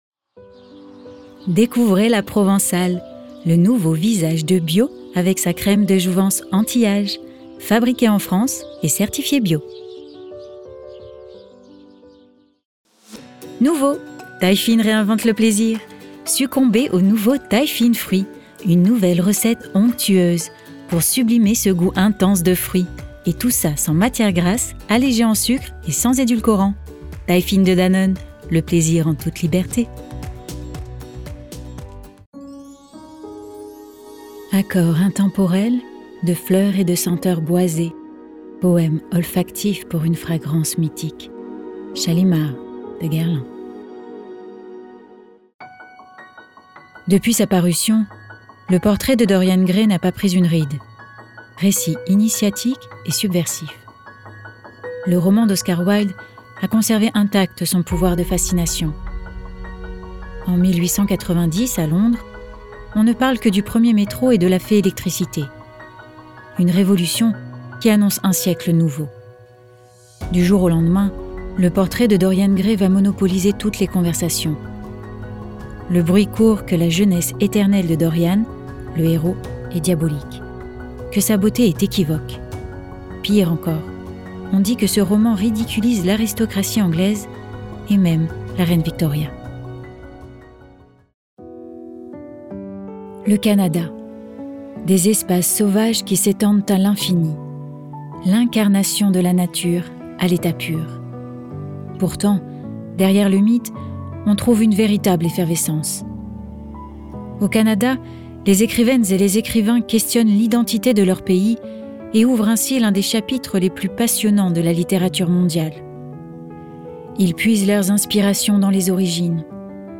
Démo Voix - Reprises
Voix off